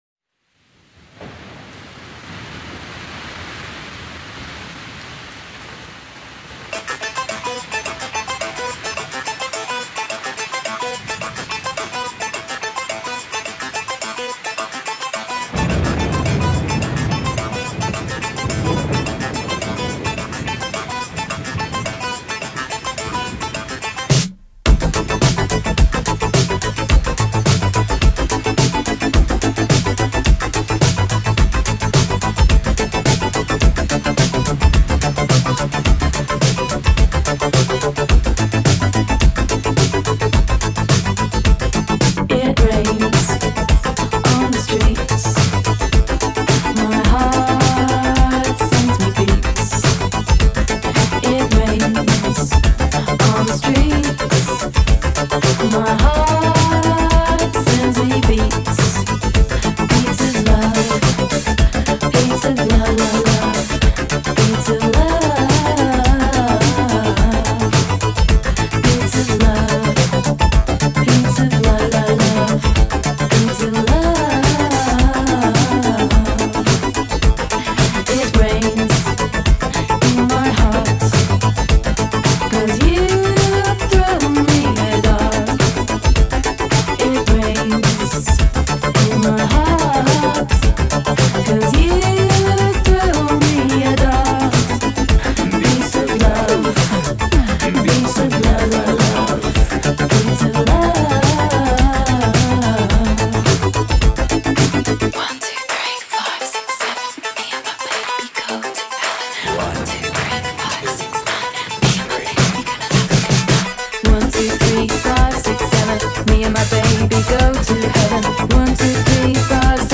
Deep House
Tech House Techno